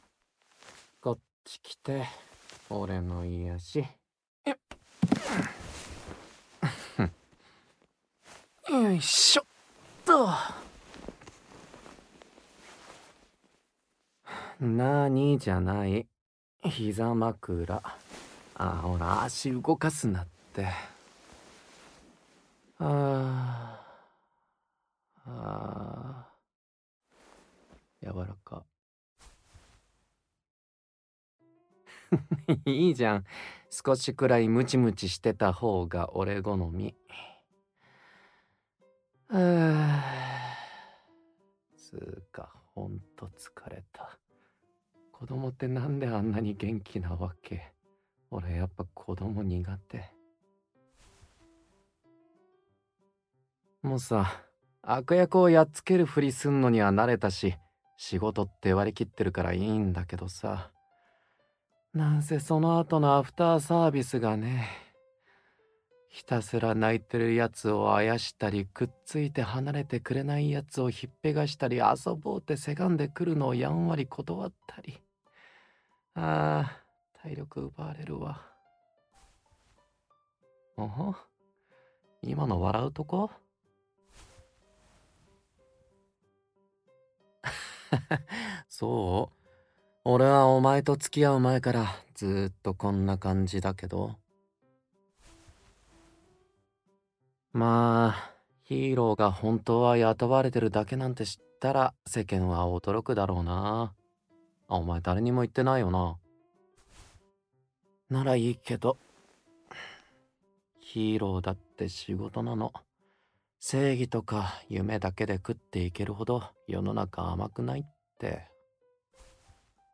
●全編ダミーヘッドマイクにて収録
というのが伝わるよう「プライベートの電源OFFモード」をイメージして演じました。